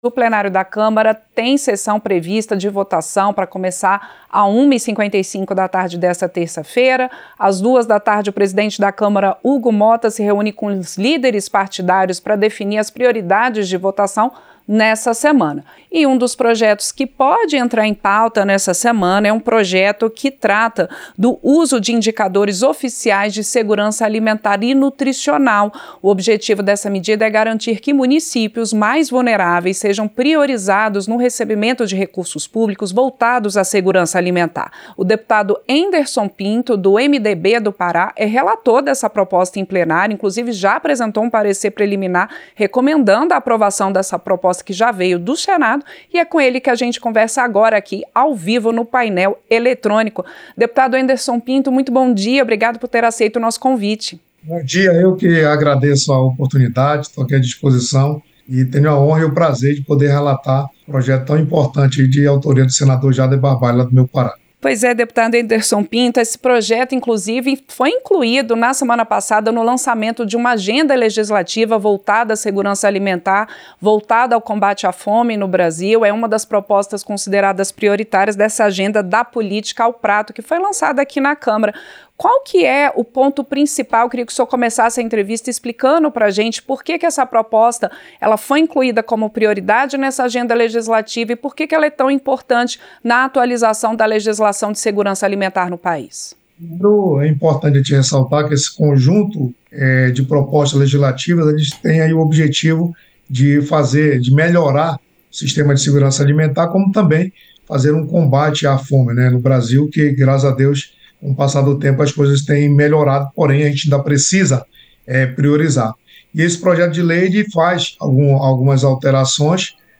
• Entrevista - Dep. Henderson Pinto (MDB-PA)
Programa ao vivo com reportagens, entrevistas sobre temas relacionados à Câmara dos Deputados, e o que vai ser destaque durante a semana.